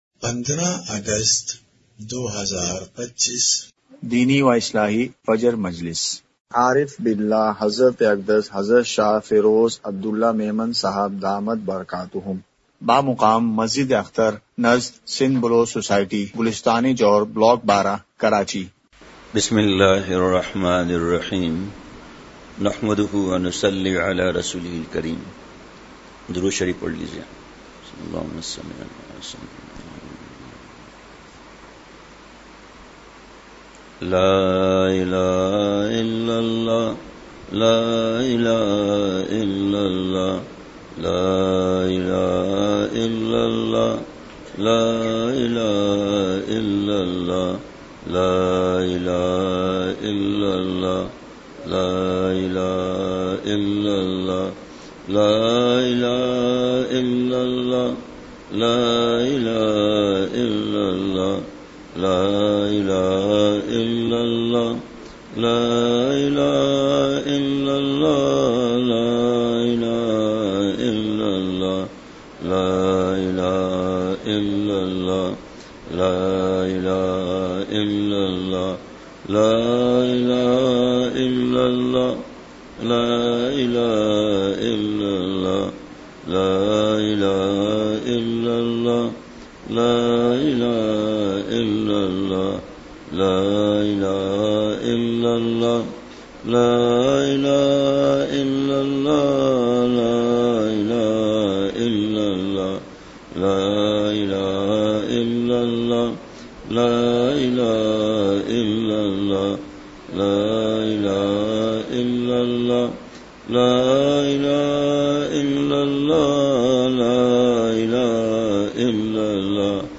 اصلاحی مجلس
مقام:مسجد اختر نزد سندھ بلوچ سوسائٹی گلستانِ جوہر کراچی